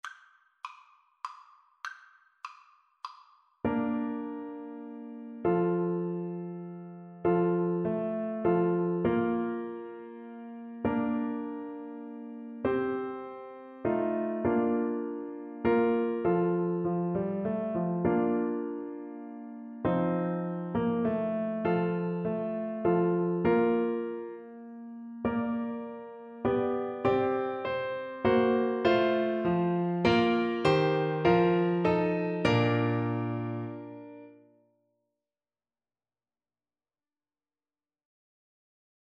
Classical Mozart, Wolfgang Amadeus Kyrie Eleison Clarinet version
Clarinet
3/4 (View more 3/4 Music)
Bb major (Sounding Pitch) C major (Clarinet in Bb) (View more Bb major Music for Clarinet )
Slow
Classical (View more Classical Clarinet Music)